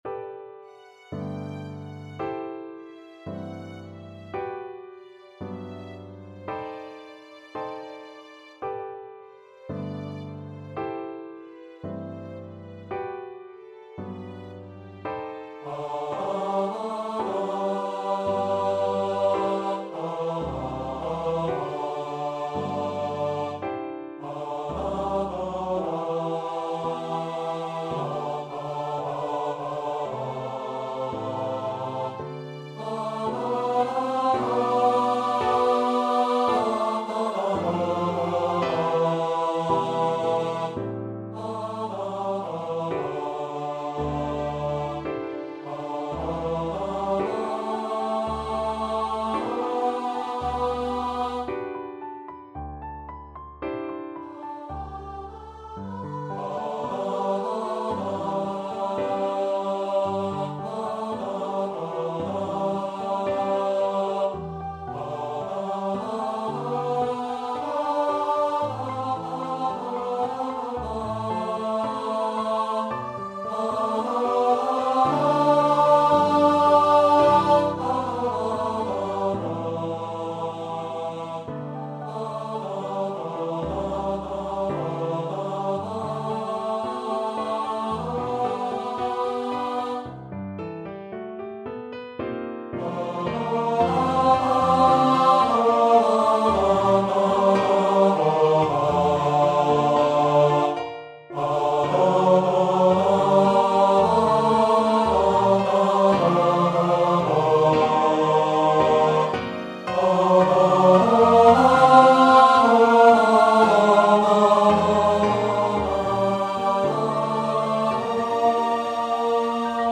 Voice 2